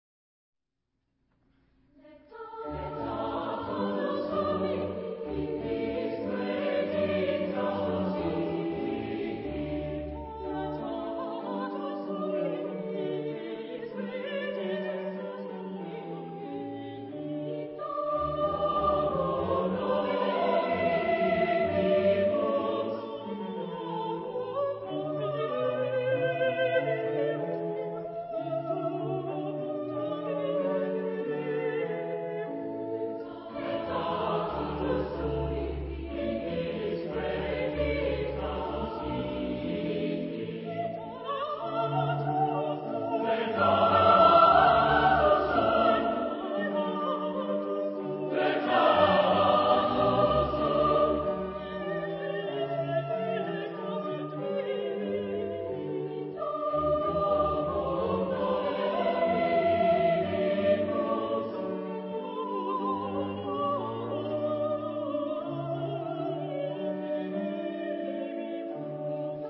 Epoque: 18th century
Genre-Style-Form: Sacred ; Baroque ; Psalm
Type of Choir: SATB  (4 mixed voices )
Instrumentation: Organ  (1 instrumental part(s))
Instruments: Harpsichord (1) or Organ (1)